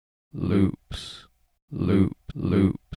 "loops" 3 sec. stereo 61k
loops.mp3